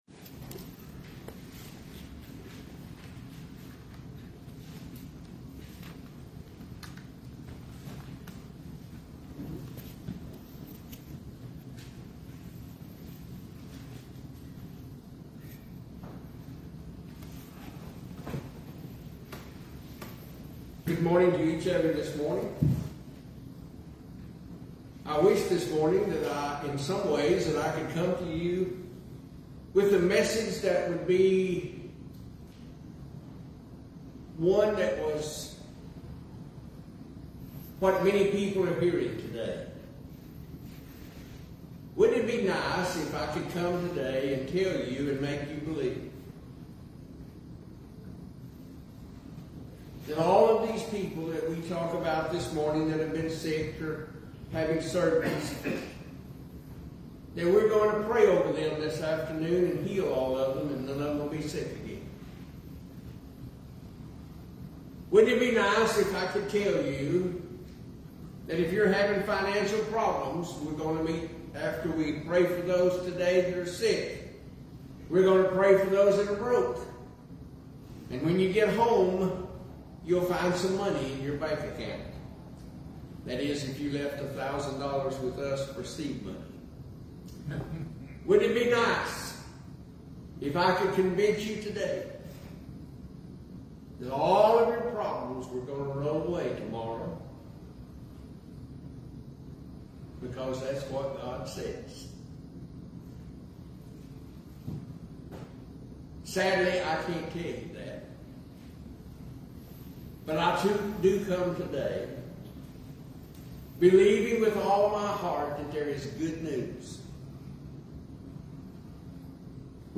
9-21-25-Waldo-Sermon.mp3